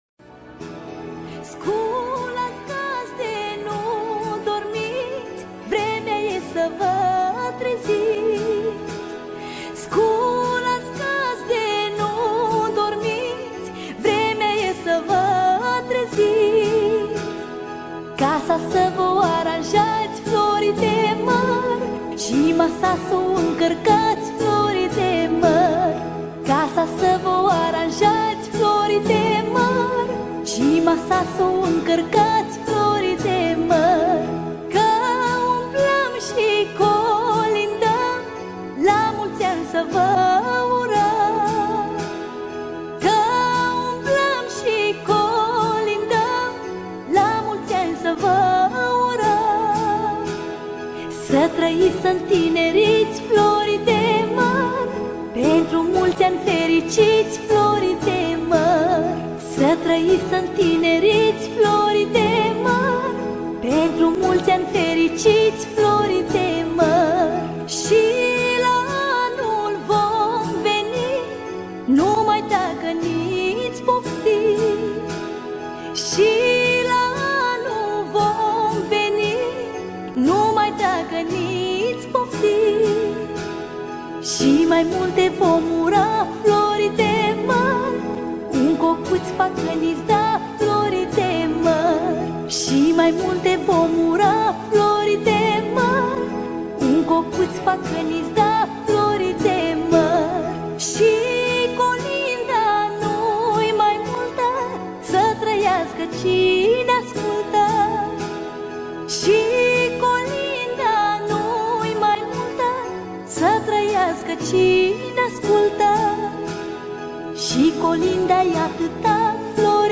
Data: 12.10.2024  Colinde Craciun Hits: 0